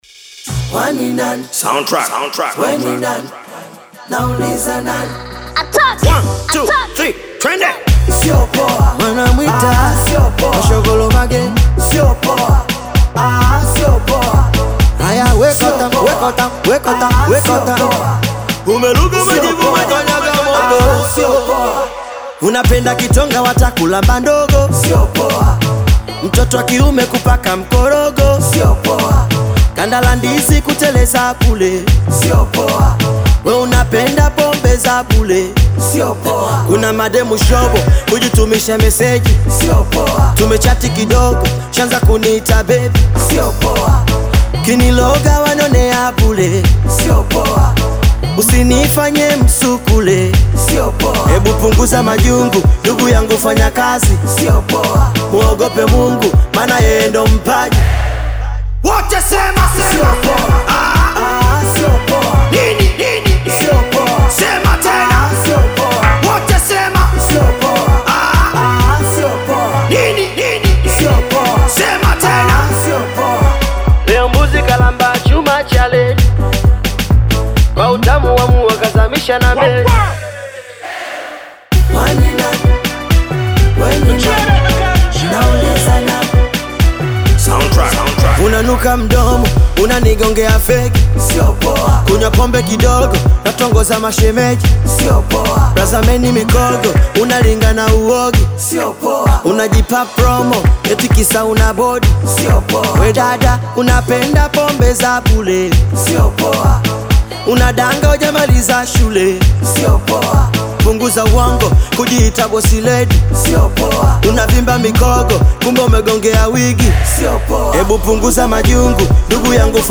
Tanzanian bongo flava artist
African Music